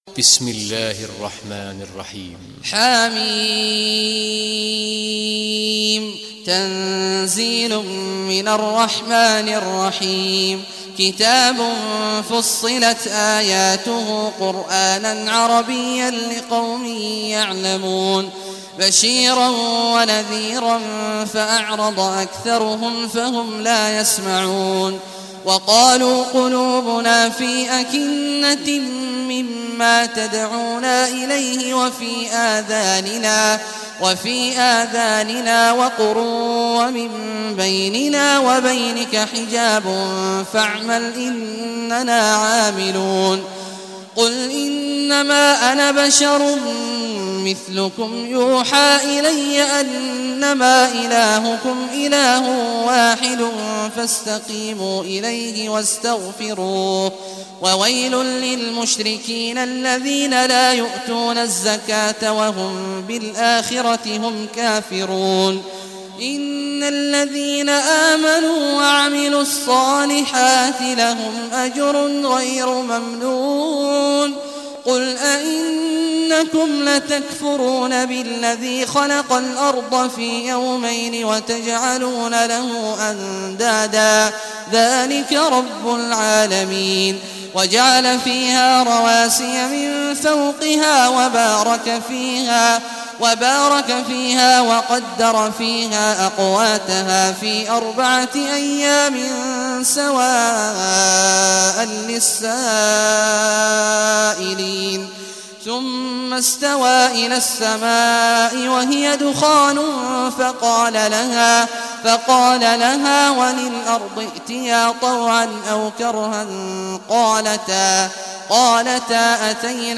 Surah Fussilat Recitation by Sheikh Awad al Juhany
Surah Fussilat, listen or play online mp3 tilawat / recitation in Arabic in the beautiful voice of Sheikh Abdullah Awad al Juhany.